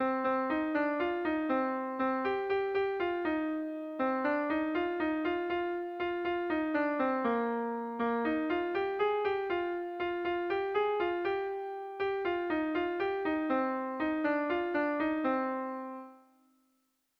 Kontakizunezkoa
Zortziko txikia (hg) / Lau puntuko txikia (ip)
ABDE